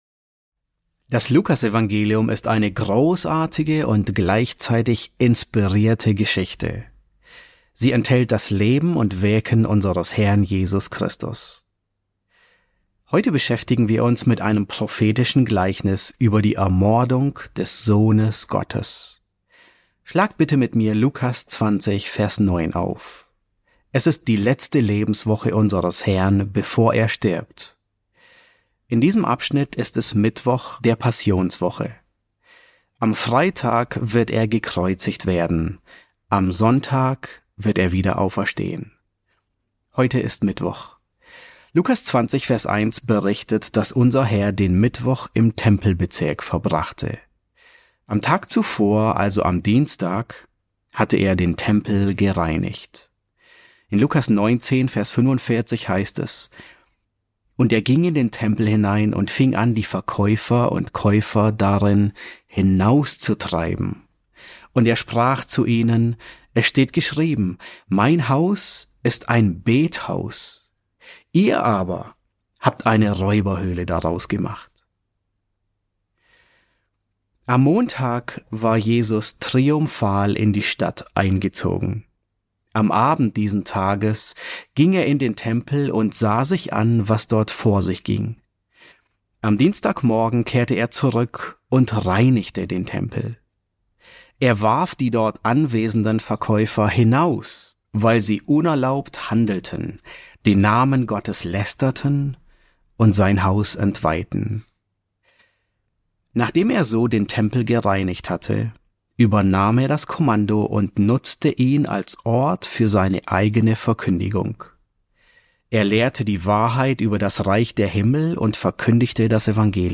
E3 S7 | Der Mord an Gottes Sohn: Ein prophetisches Gleichnis, Teil 1 ~ John MacArthur Predigten auf Deutsch Podcast